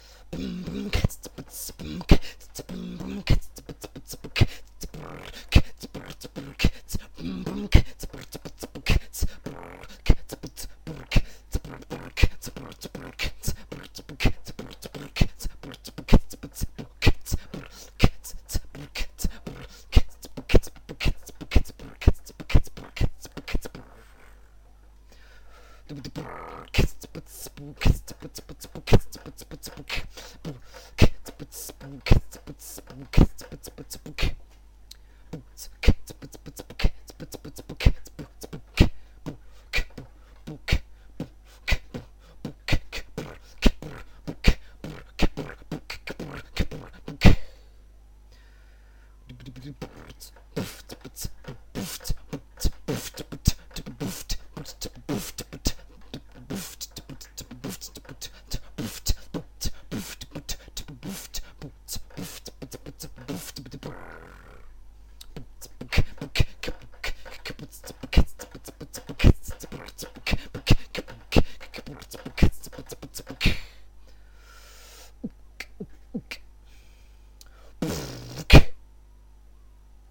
Оцените мой фристаил...
Занимаюсь офицально всего около месяца,а так 2 недели...)...Нормально у меня получается или нет?...(Хендклэп страдает поэтому делаю из себя)
вот снэр ниочём)) а хэнд клэп звучит как римшот, хай хэты ... пока сойдёт